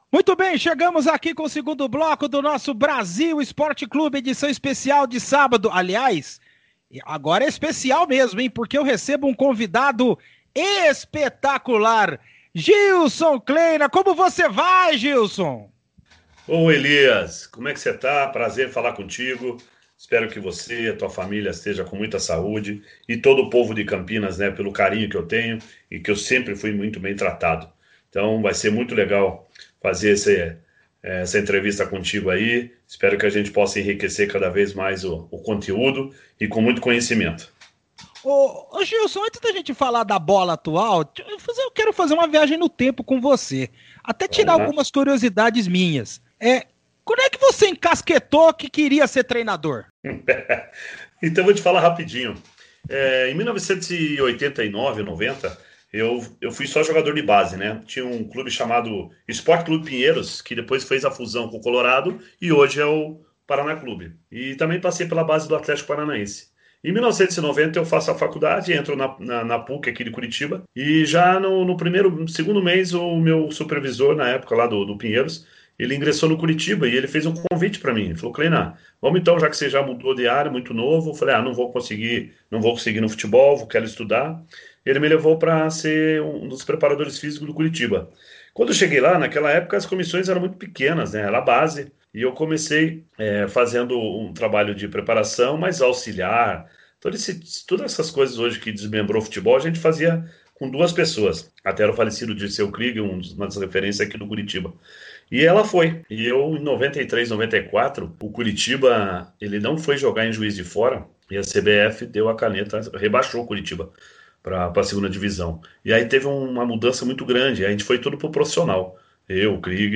Ainda na espera de uma nova oportunidade, o técnico Gilson Kleina concedeu uma entrevista para a Rádio Brasil e que foi reproduzida neste sábado, dia 01º de maio.